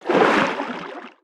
Sfx_creature_snowstalker_swim_05.ogg